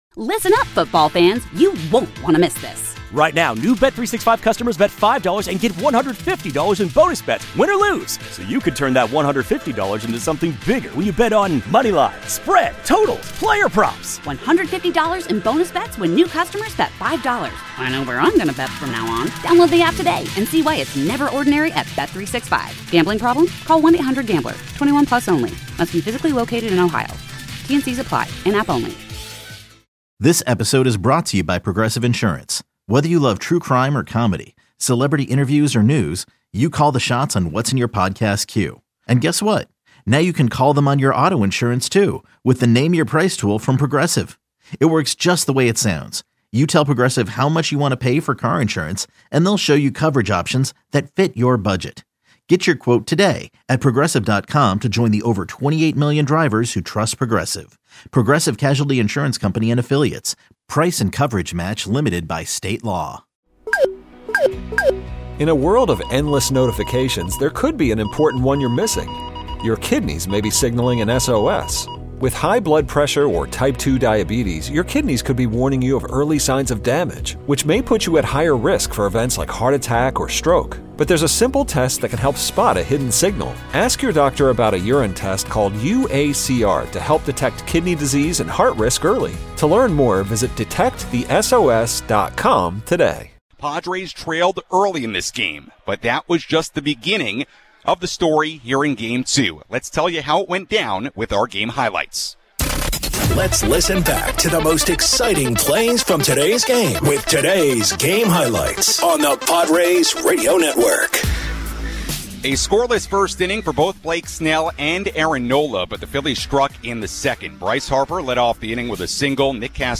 Play-by-play calls on the Padres Radio Network